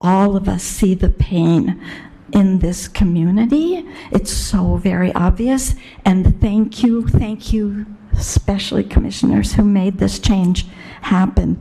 Kalamazoo city commissioners held a public hearing for their 2025 budget, announcing a late amendment to set aside money for the homeless.
During the public hearing on the budget plan